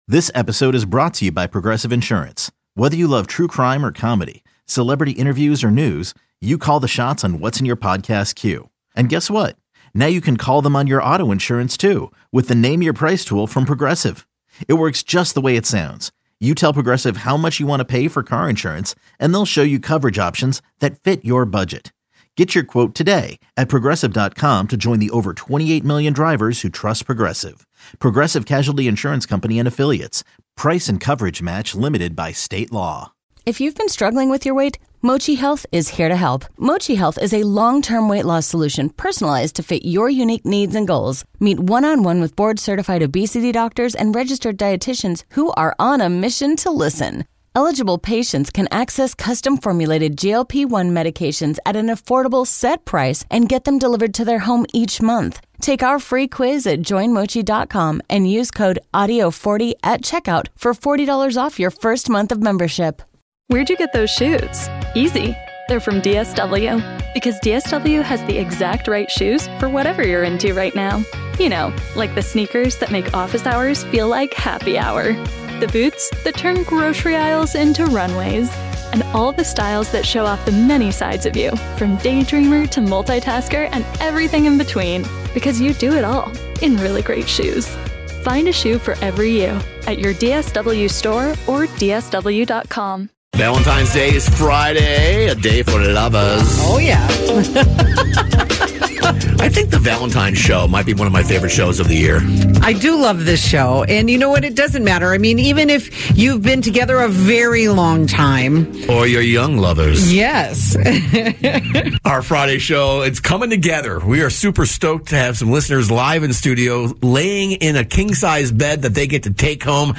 Interviews, favorite moments